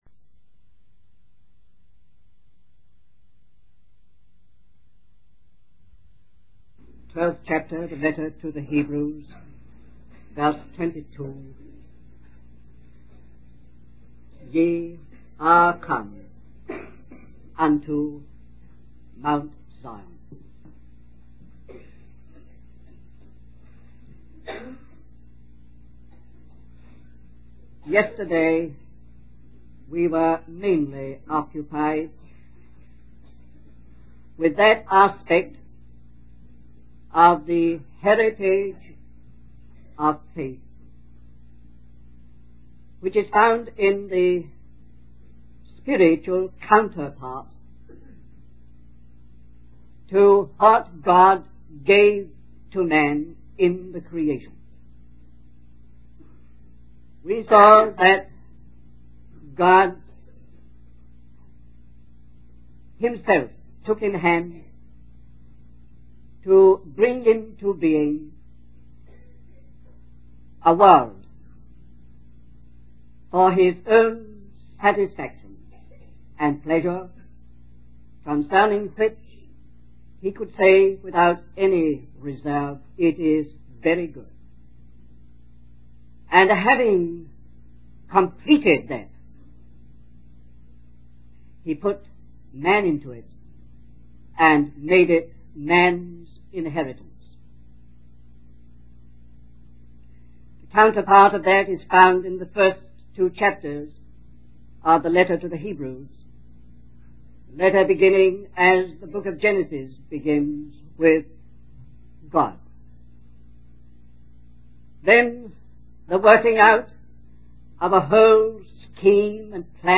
In this sermon, the speaker emphasizes the importance of the message being shared during their gatherings.